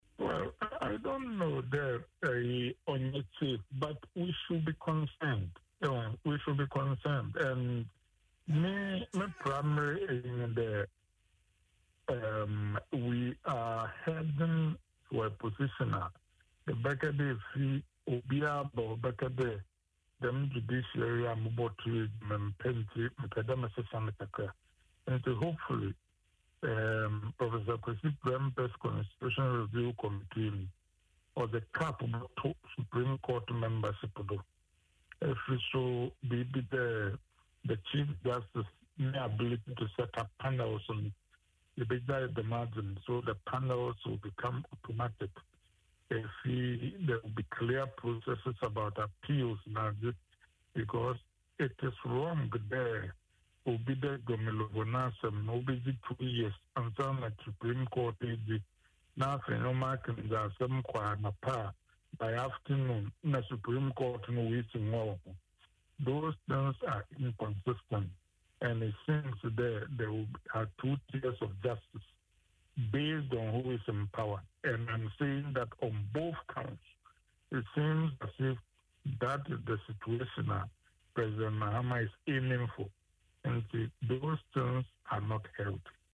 In an interview on Adom FM’s Dwaso Nsem, he stated that recent developments in the country give the impression that the Judiciary can be easily manipulated.